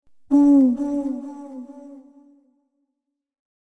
Owl Bouton sonore